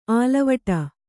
♪ ālavaṭa